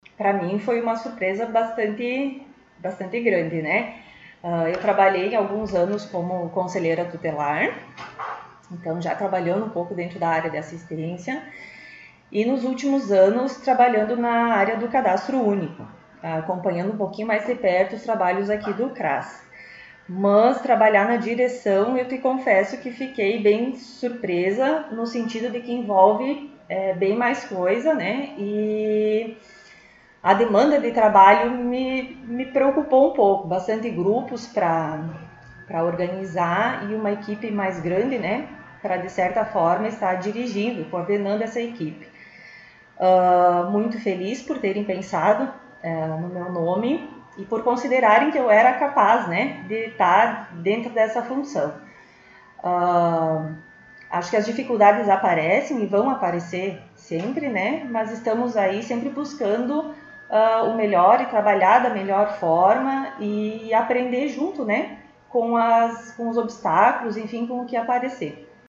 Diretora do CRAS concedeu entrevista